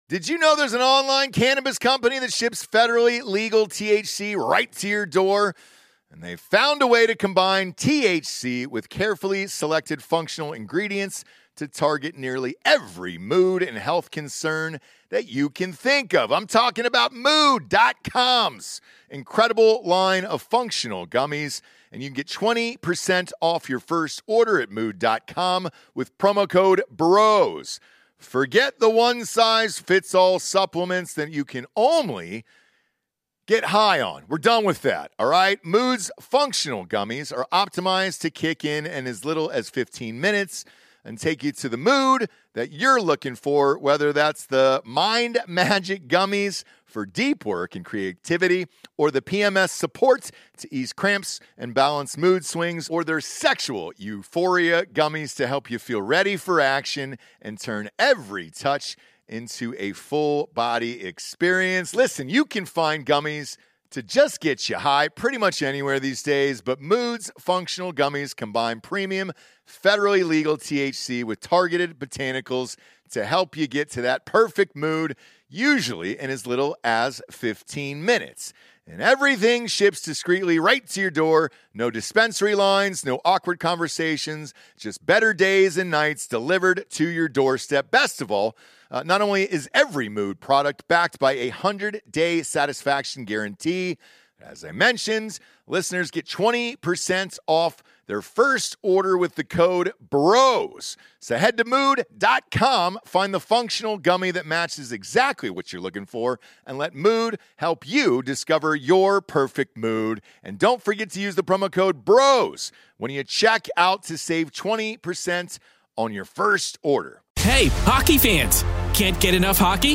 NFL Hall of Famer and Chicago Bears legend Brian Urlacher joins the show to talk about the NFL's awful refereeing, why Urlacher is a bigger Cowboys fan than he is a Bears fan (and what he thinks of the Cowboys' chances this year), why he loves Dak Prescott, and what he'd change about the NFL today.